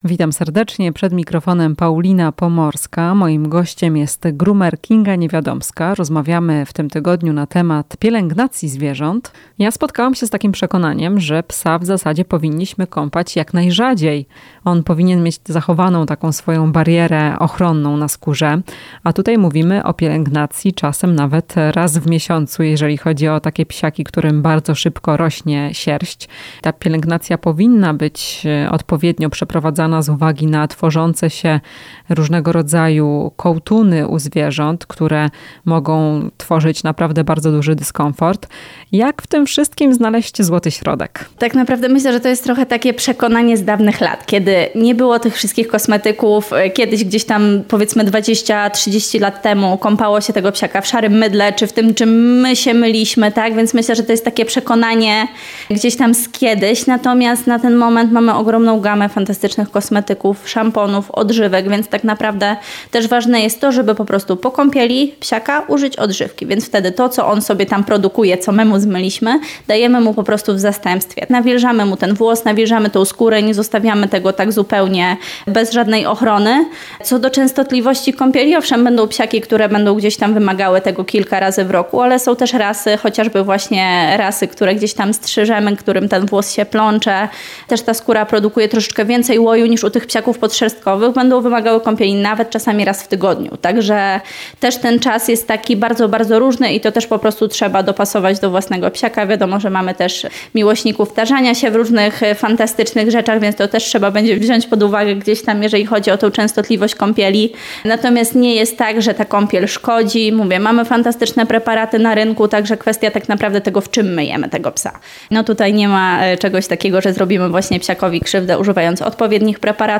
W "Chwili dla pupila" wyjaśniamy jak często powinno się kąpać psa? Rozmowa